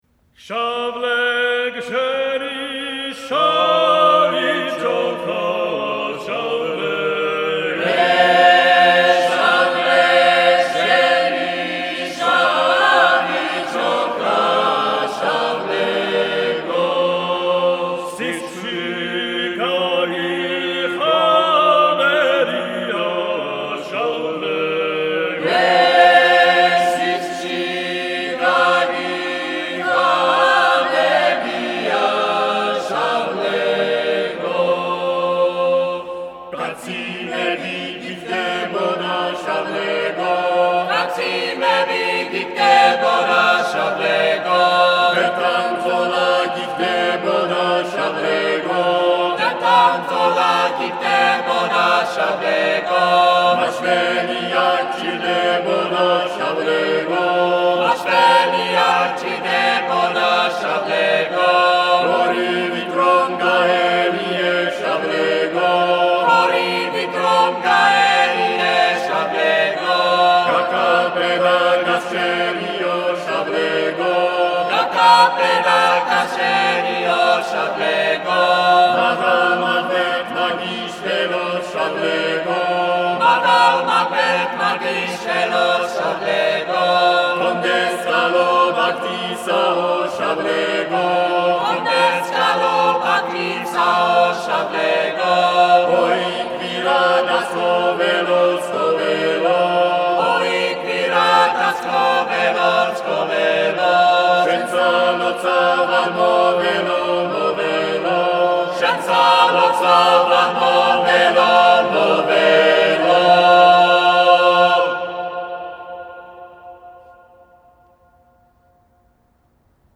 Folk Music from the Republic of Georgia